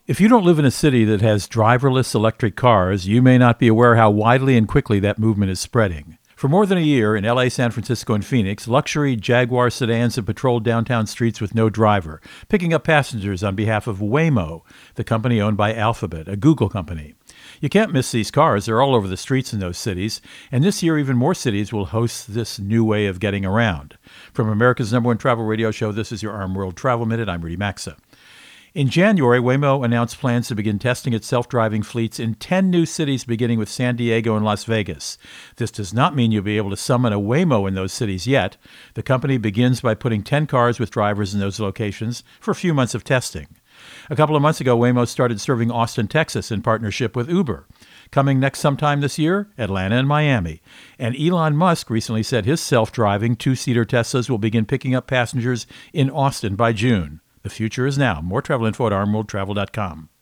Co-Host Rudy Maxa | Revolution in Driverless Cars